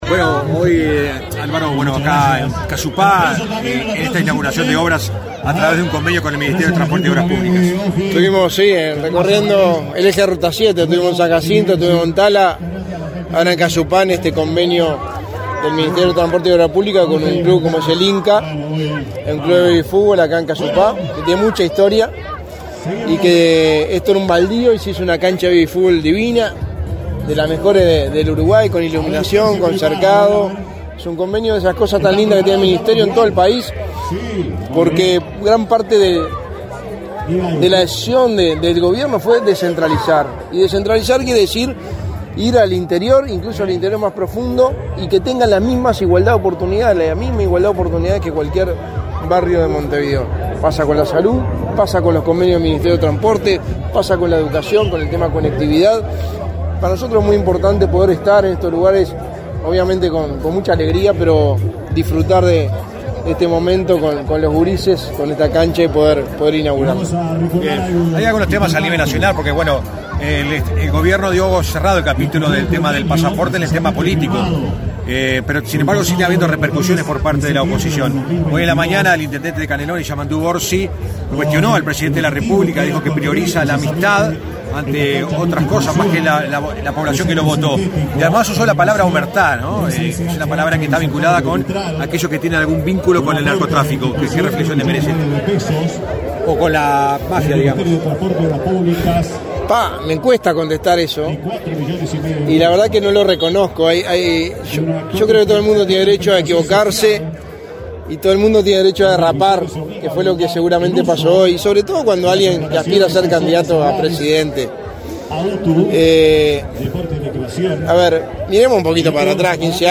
Declaraciones a la prensa del secretario de la Presidencia, Álvaro Delgado